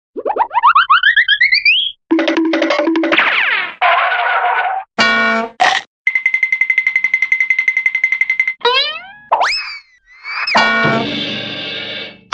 Звуковые эффекты из мультфильма Том и Джерри